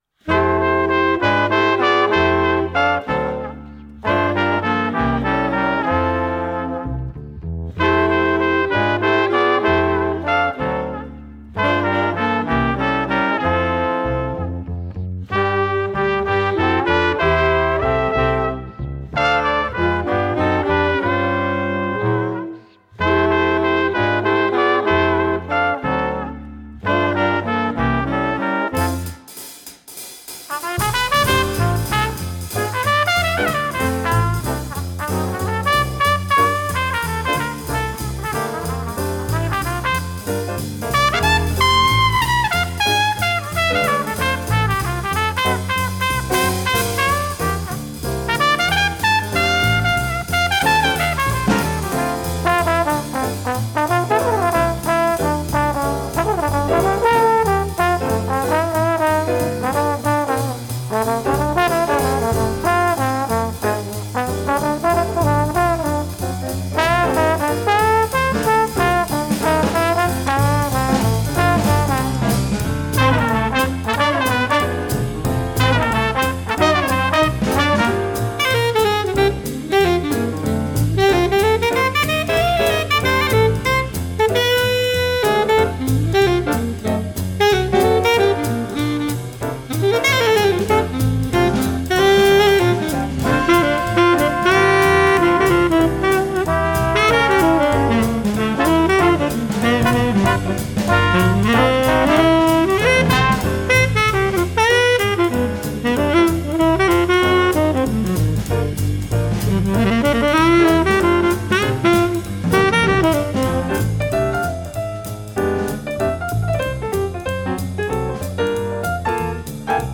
Traditional folk tunes and poular Swiss songs